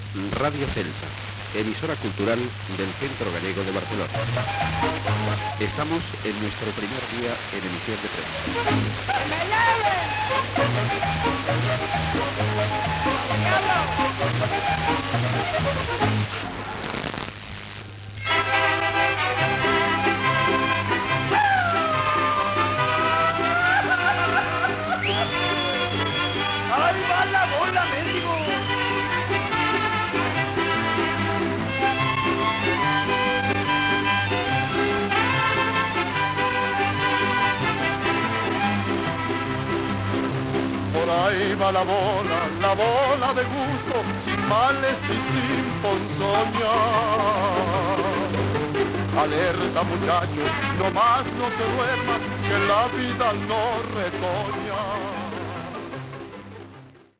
bf27dc9c1a3a925ef4a776d549f45c76f5c79c99.mp3 Títol Radio Celta Emissora Radio Celta Titularitat Tercer sector Tercer sector Cultural Descripció Identificació de l'emissora i tema musical. Data emissió 1984-12 Banda FM Localitat Barcelona Comarca Barcelonès Durada enregistrament 00:57 Idioma Castellà Notes Primer dia d'emissió en proves.